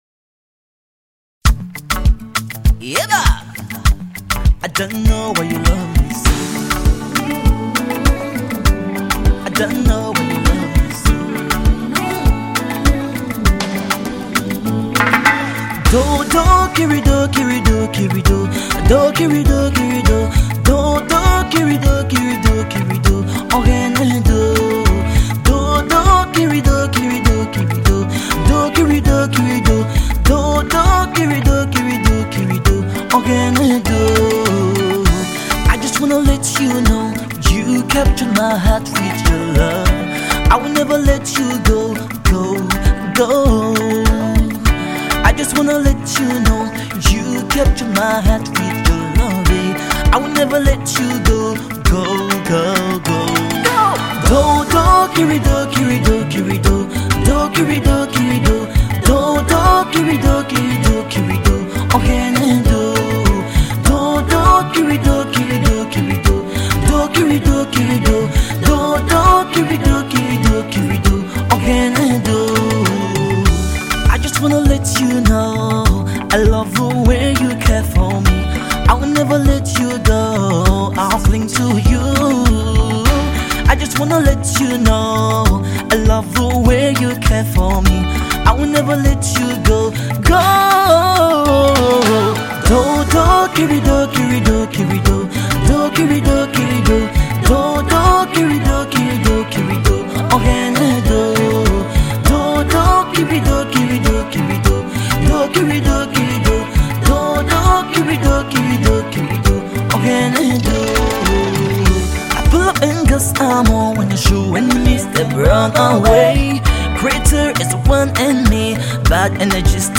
praise vibe